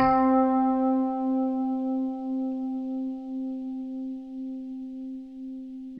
Rhodes - Puff.wav